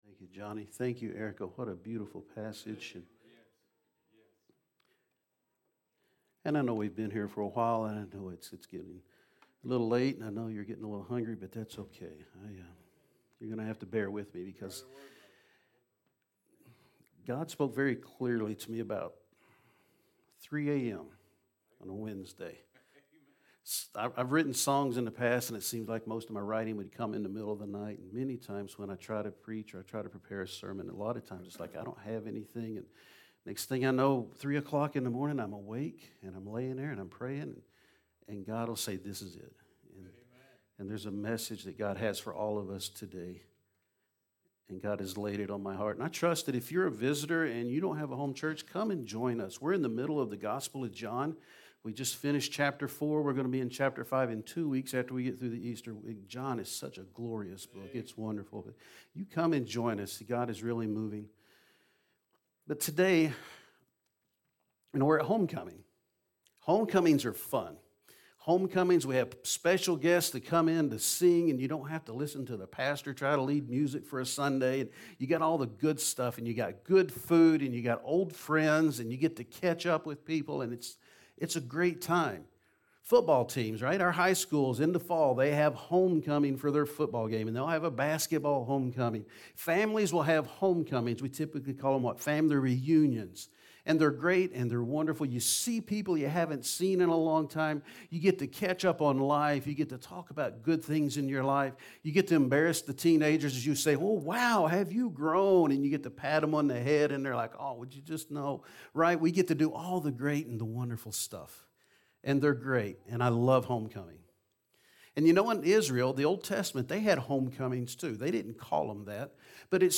Homecoming Sermon